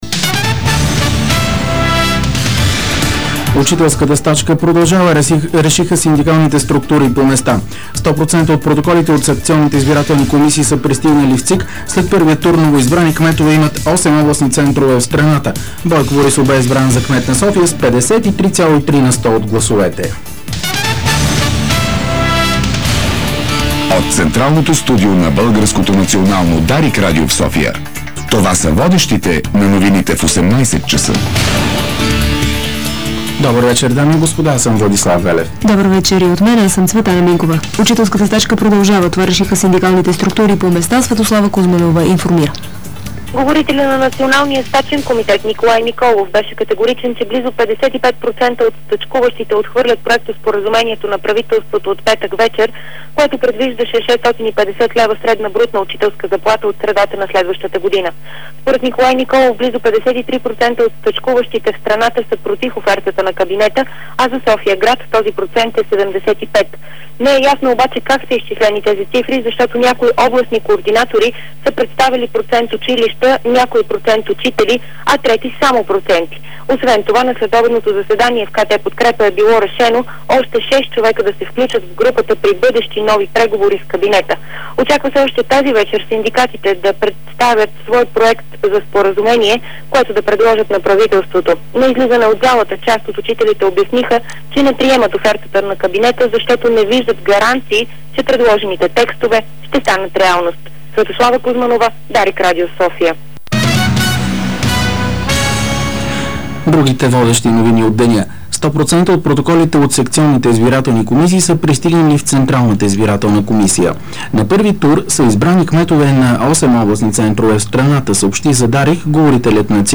Обзорна информационна емисия - 29.10.2007 г.